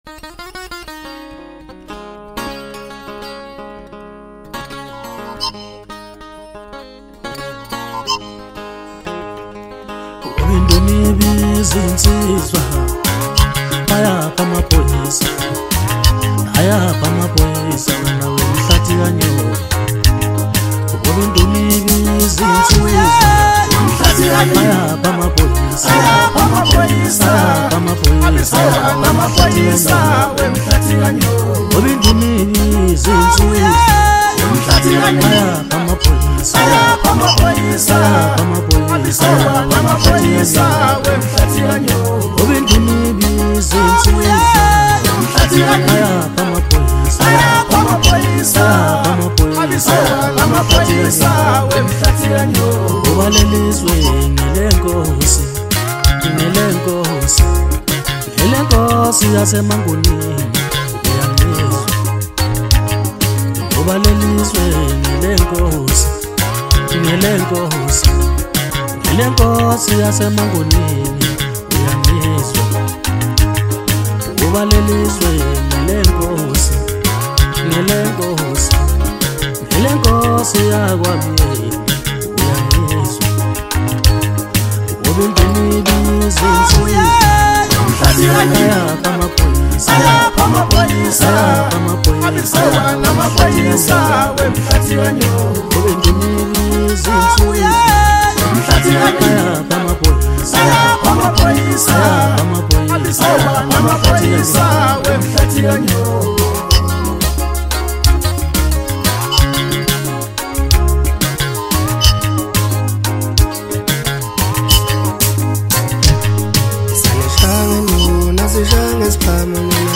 Home » Maskandi » DJ Mix » Hip Hop
South African singer-songwriter